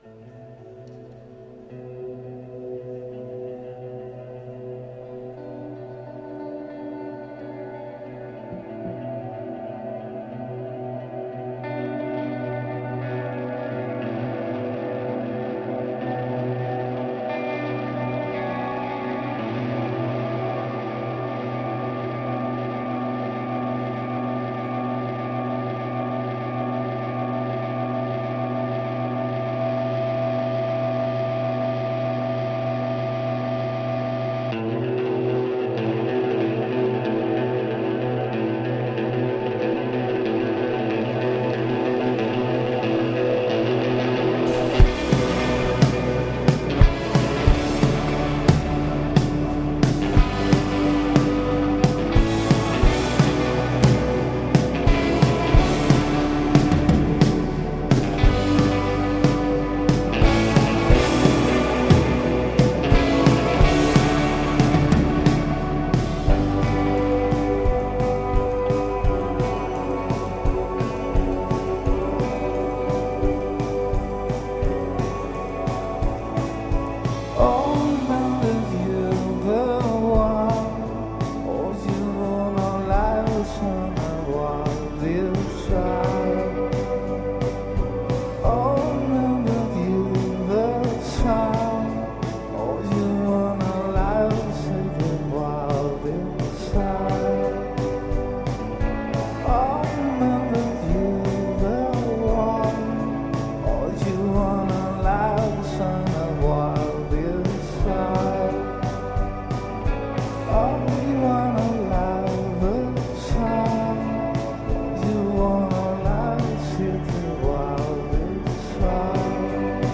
Spanish Shoegaze/Indie/Minimalist band
Piano and Bass
drums
A Spanish slant on Shoegaze/Indie – like it never went away.